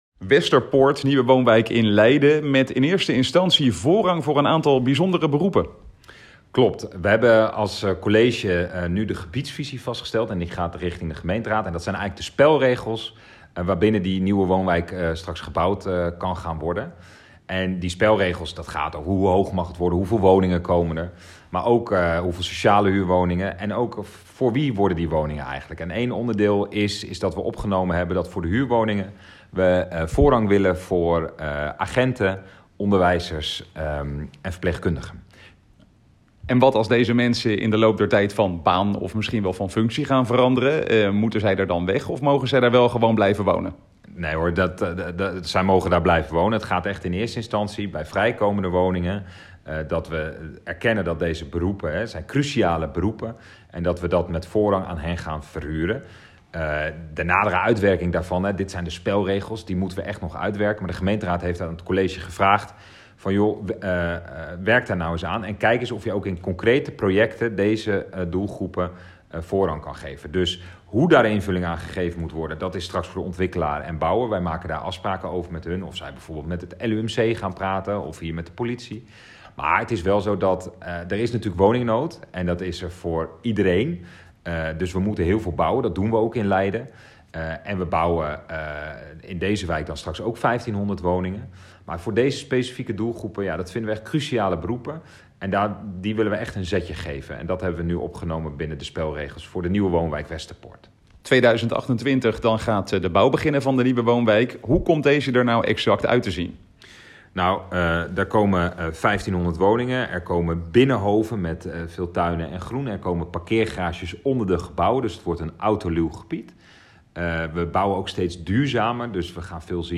Verslaggever
in gesprek met wethouder Julius Terpstra.